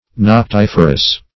noctiferous - definition of noctiferous - synonyms, pronunciation, spelling from Free Dictionary
Search Result for " noctiferous" : The Collaborative International Dictionary of English v.0.48: Noctiferous \Noc*tif"er*ous\, a. [L. noctifer; nox, noctis + ferre to bring.]